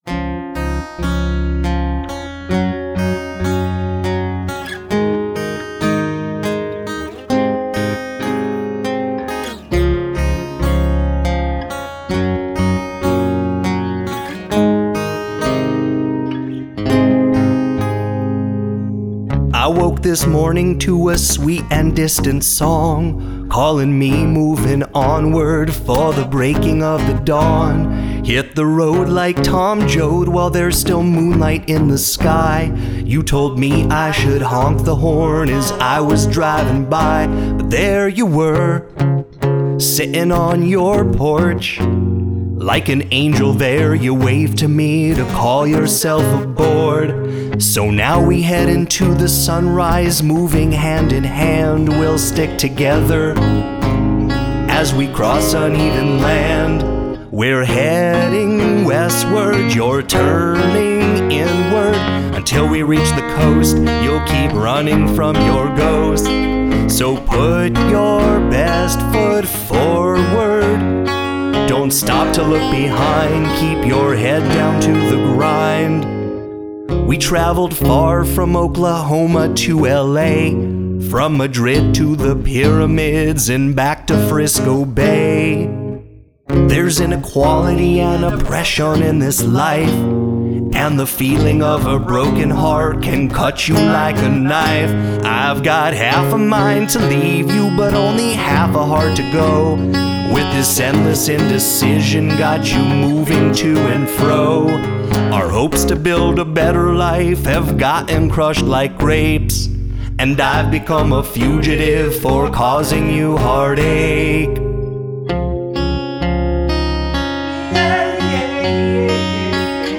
the acoustic folk/funk/blues music
singer / songwriter / guitarist / harmonica player